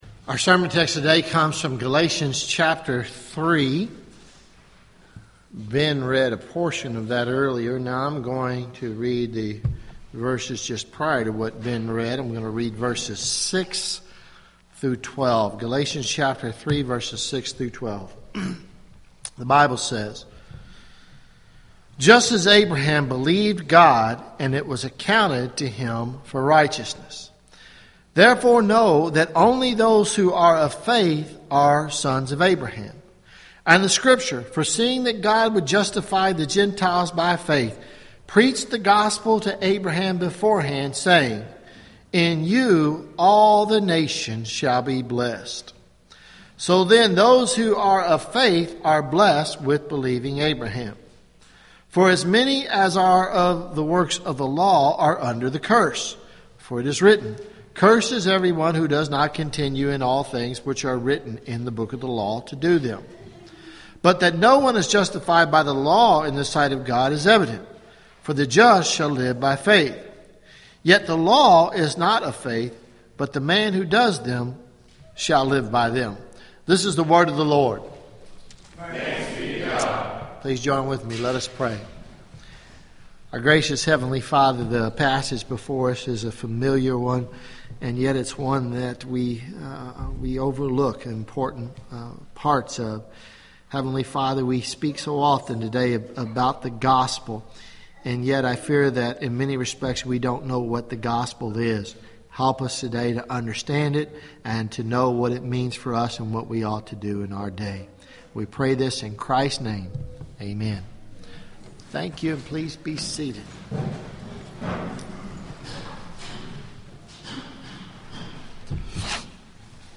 Sermons Dec 14 2014 “All Nations Blessed